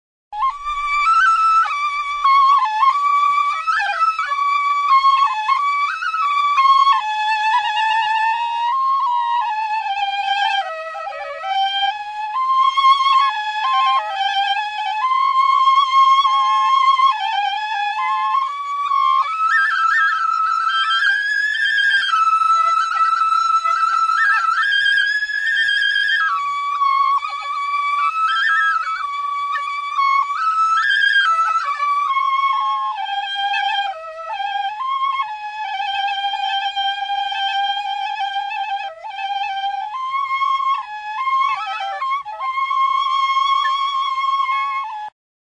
SULING; Flauta | Soinuenea Herri Musikaren Txokoa
Aérophones -> Flûtes -> Á bec (á deux mains) + kena
Bi eskuko flauta zuzena da. 6 zulo ditu aurrekaldean.